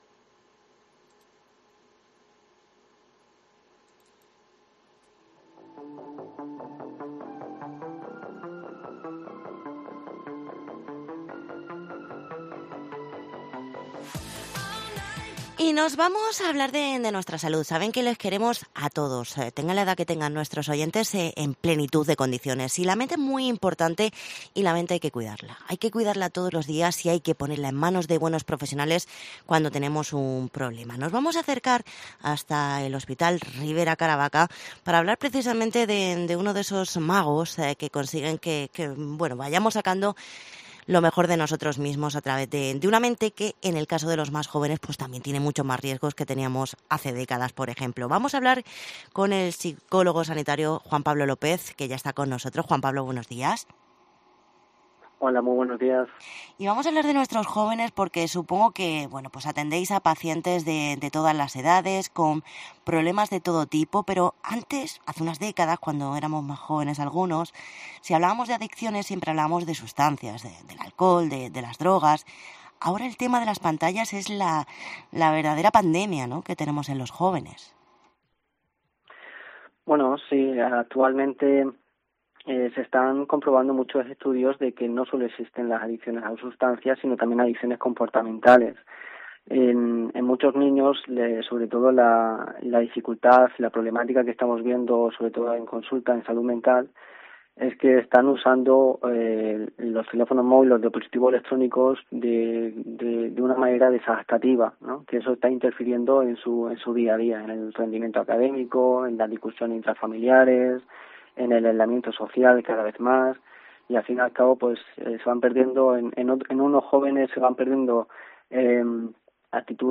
El psicólogo